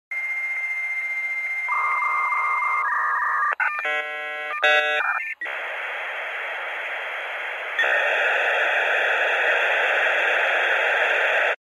На этой странице собраны звуки, передающие атмосферу размышлений: задумчивые паузы, едва уловимые вздохи, фоновое бормотание.
Звук, коли людина думає або зависає